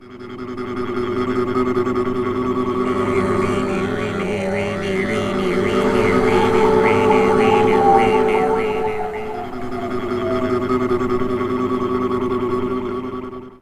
Vous avez vu le concours Canard PC sur leur site ? imiter un son de sort magique à la bouche 8)